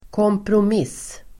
Uttal: [kåmprom'is:]